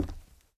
tbd-station-14/Resources/Audio/Effects/Footsteps/floor5.ogg